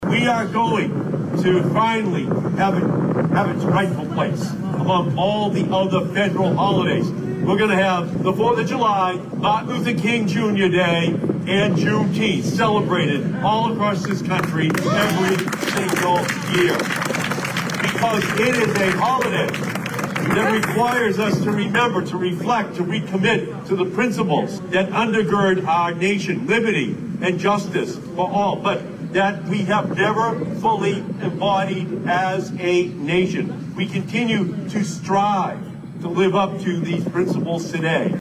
Senator Ed Markey spoke about his efforts to get the holiday Federally recognized, a task achieved roughly two years ago.